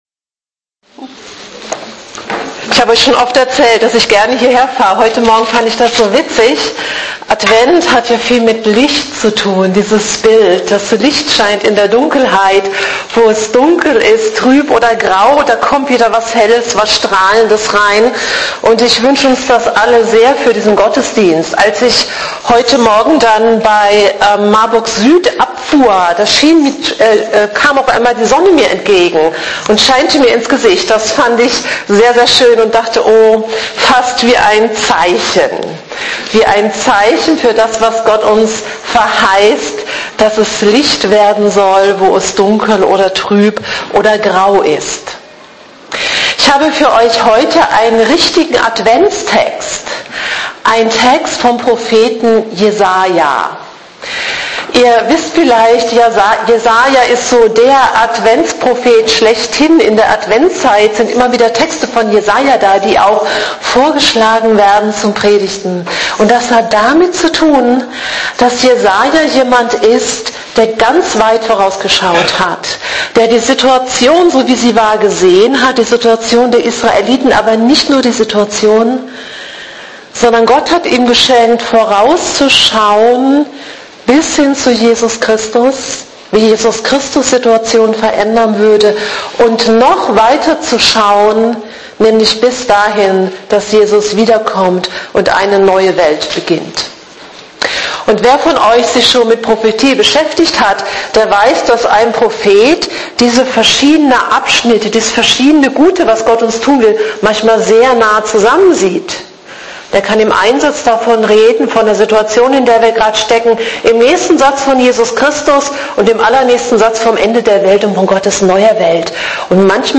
in ihrer Predigt vom 7.